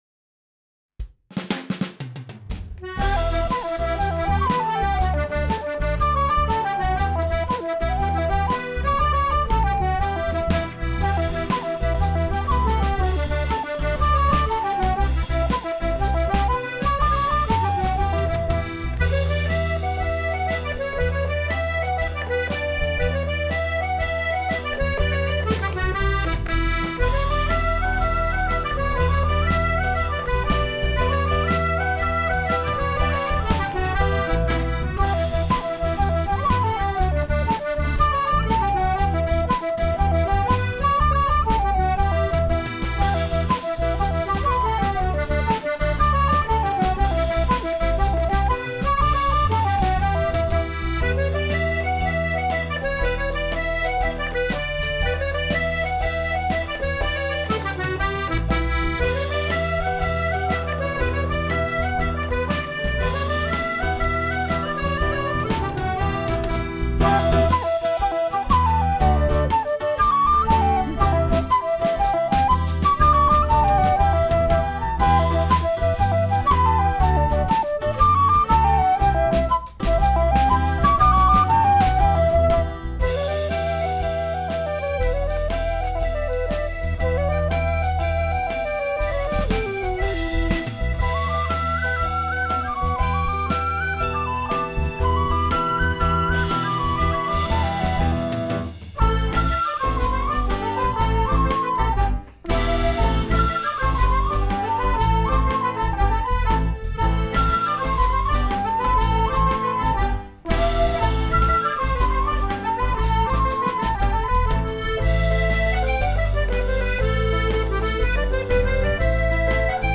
Two favourite jigs.
Recorded during Covid19 lockdown 2020